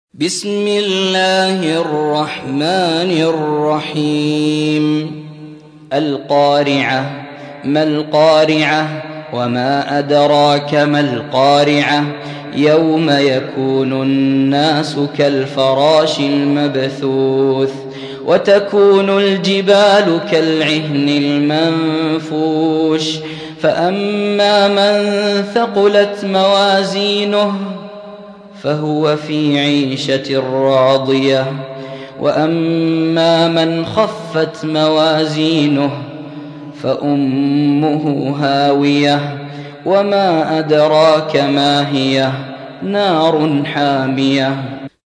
101. سورة القارعة / القارئ